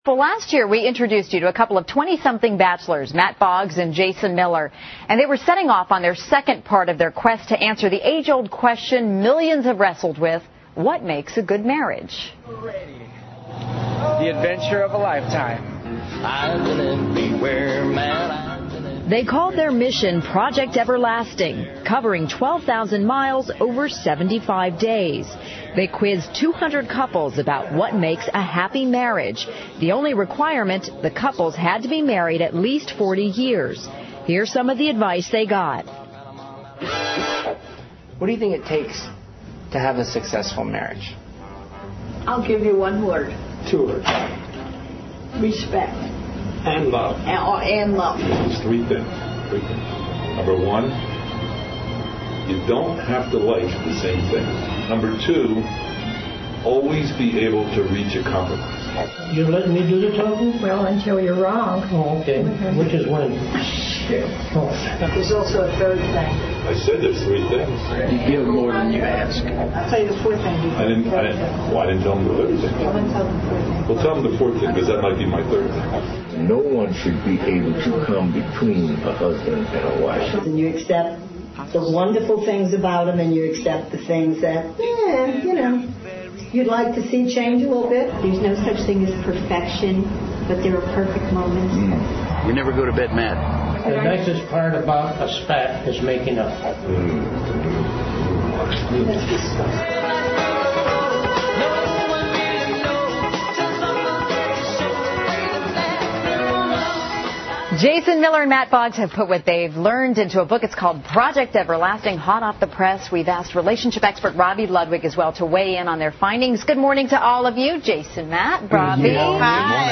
访谈录 Interview 2007-06-11&06-13, 执子之手，与子偕老 听力文件下载—在线英语听力室